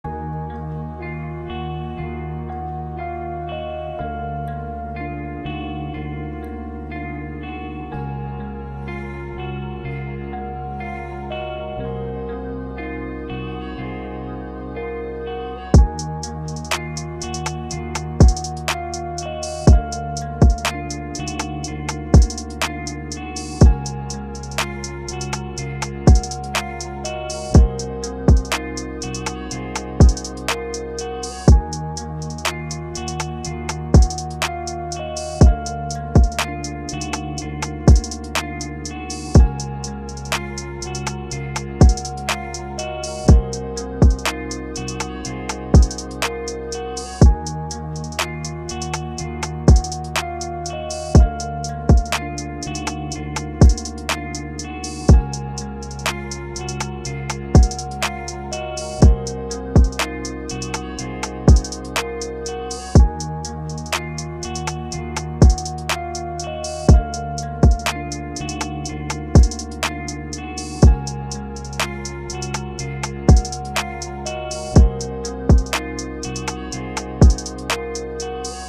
R&B
Eb Minor